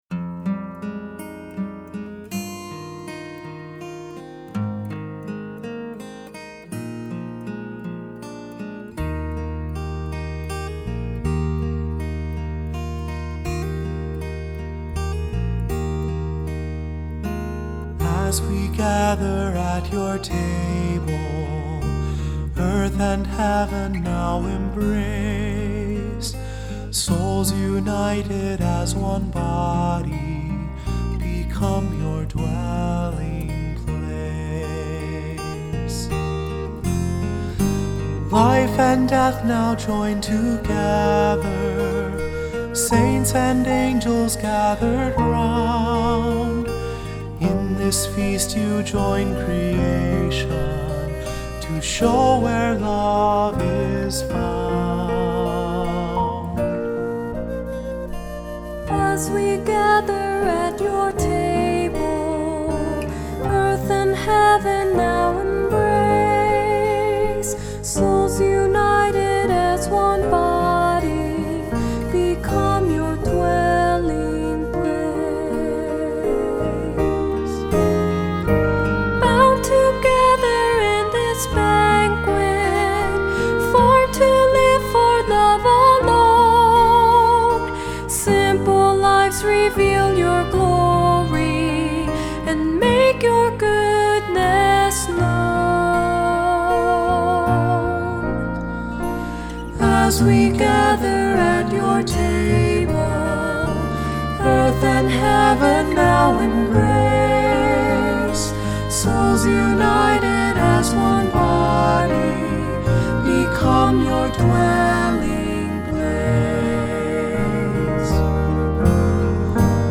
Accompaniment:      Keyboard, Flute;Oboe
Music Category:      Christian
The 2-part writing is ideal for small choirs.